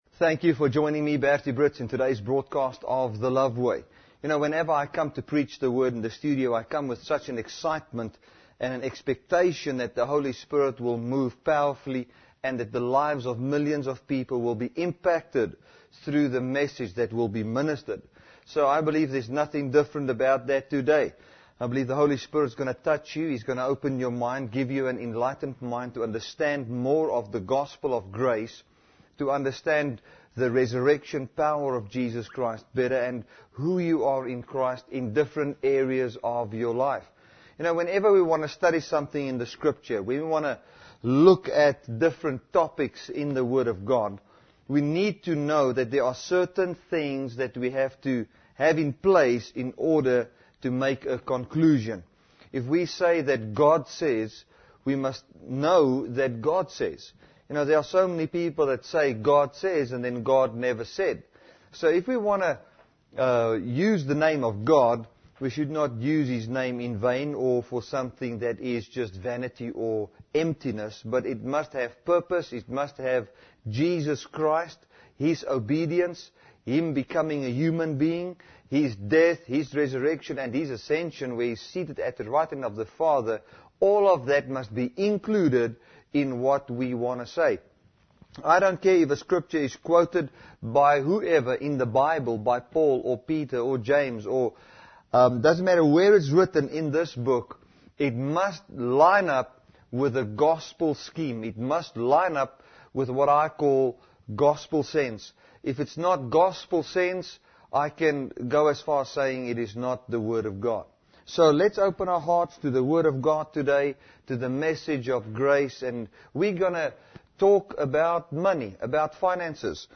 Audio Messages for Dynamic Love Ministries.